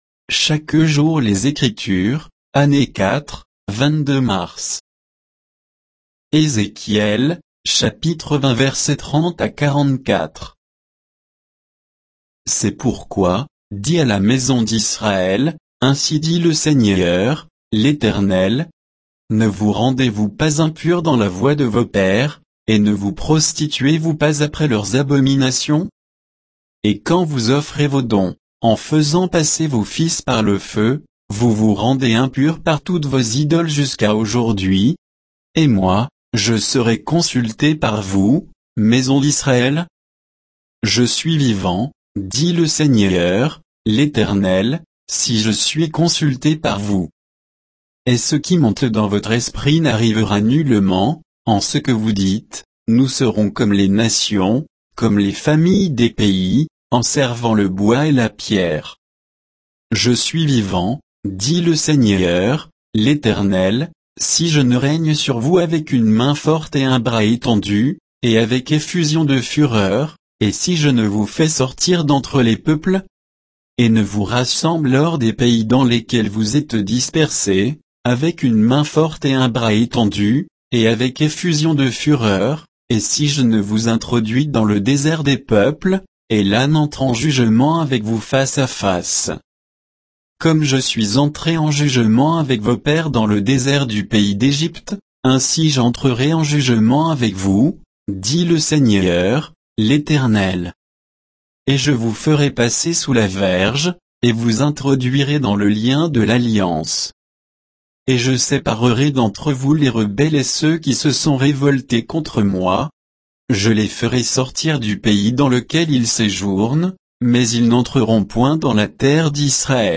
Méditation quoditienne de Chaque jour les Écritures sur Ézéchiel 20